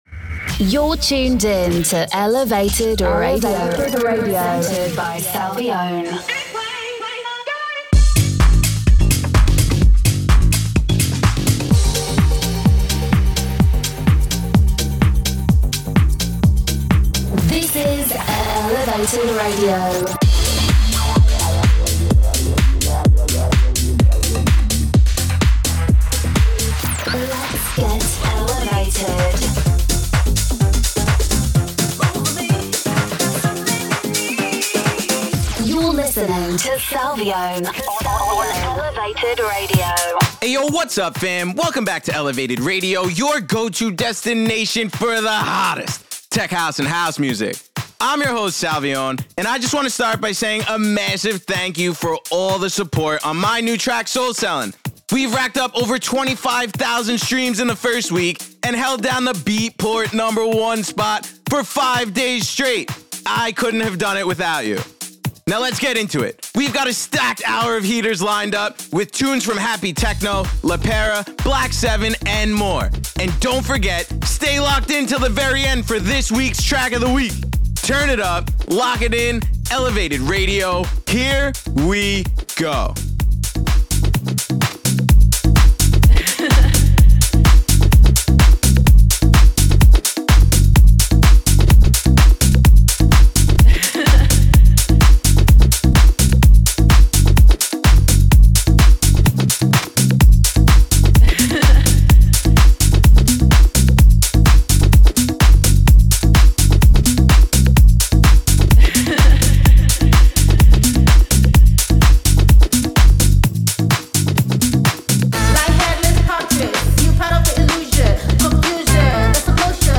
The weekly radio show